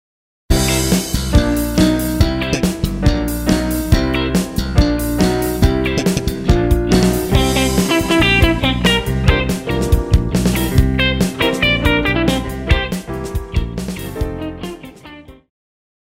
爵士
套鼓(架子鼓)
乐团
演奏曲
现代爵士
独奏与伴奏
有主奏
有节拍器